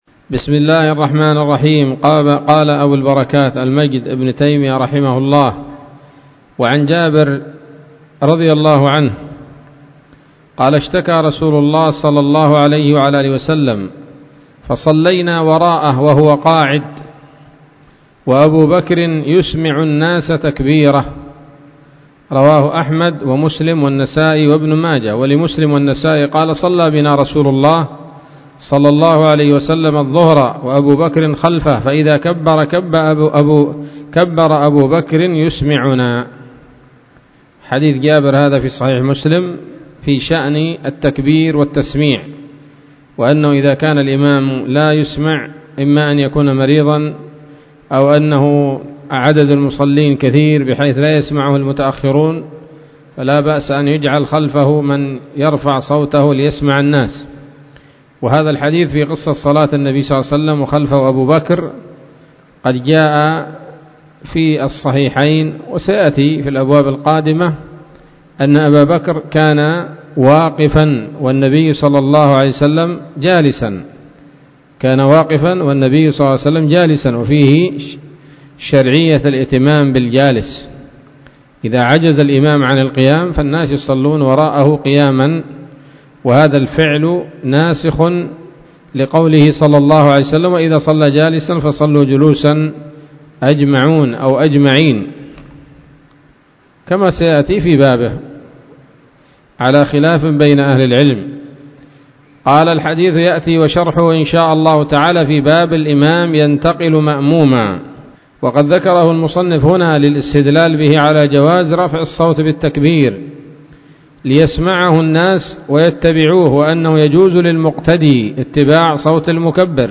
الدرس الرابع والخمسون من أبواب صفة الصلاة من نيل الأوطار